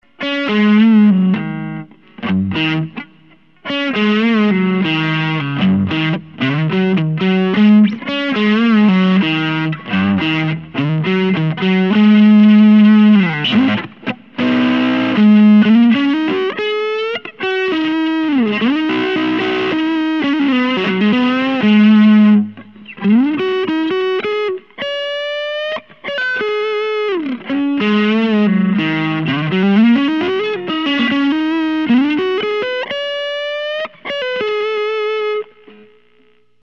Woman Tone
Crank up the Filter of the Fat Rat and get a smooth sustaining distortion sound that reminds me of a Big Muff.
All clips recorded through my AX84 tube amp set for a clean neutral tone using a stock 1970 Fender Stratocaster - miked with an SM-57. All distortion is being produced by the pedal.